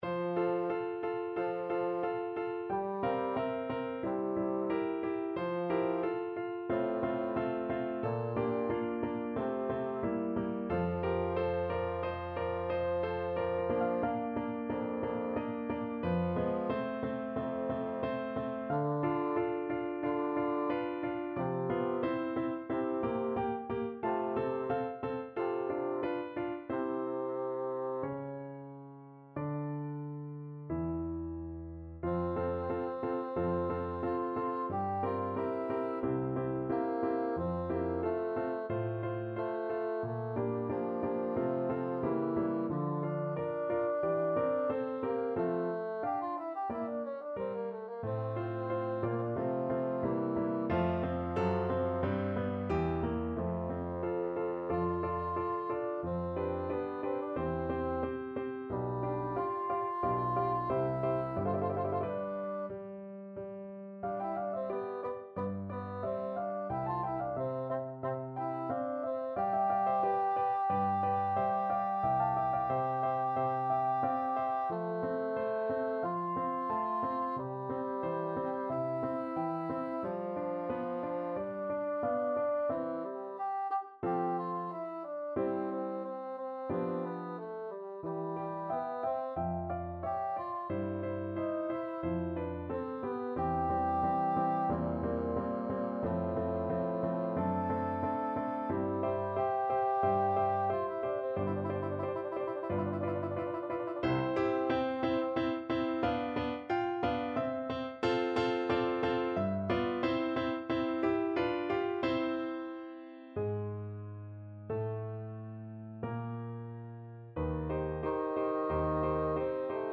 Bassoon
F major (Sounding Pitch) (View more F major Music for Bassoon )
2/2 (View more 2/2 Music)
=45 Andante ma Adagio
mozart_k191_bassoon_con_2nd_BN.mp3